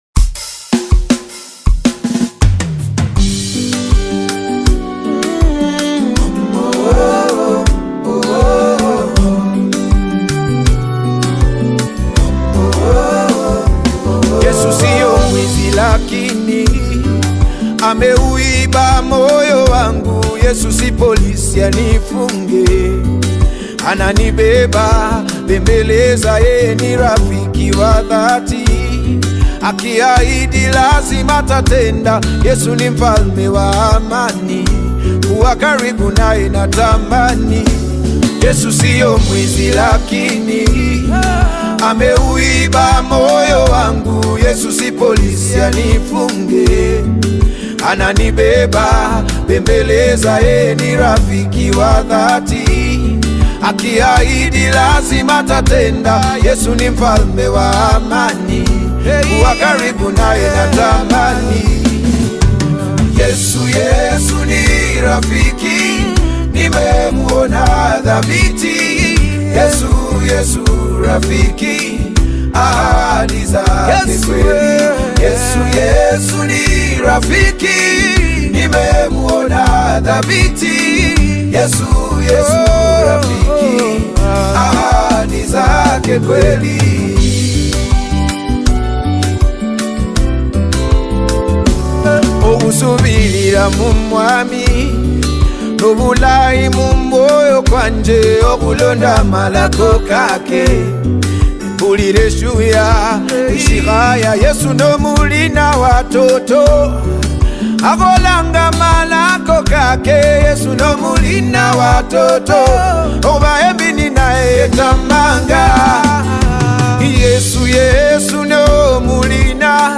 Kenyan gospel star